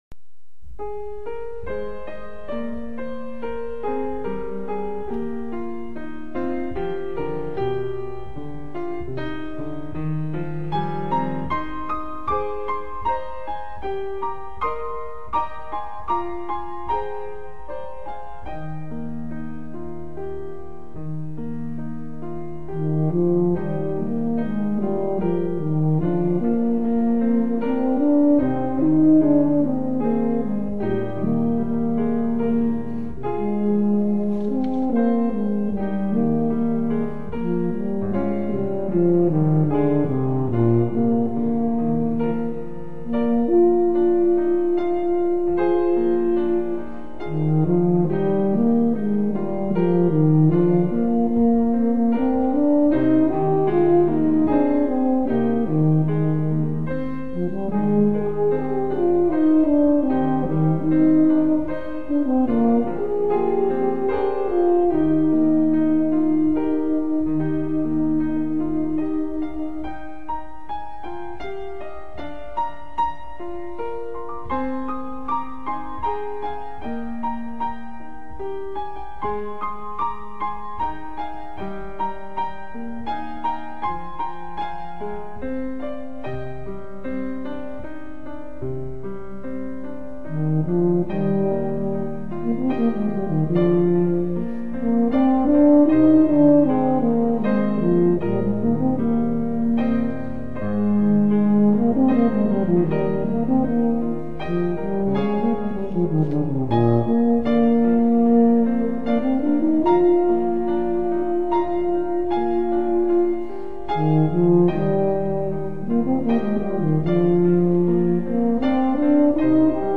For Euphonium Solo
Arranged by . with Piano.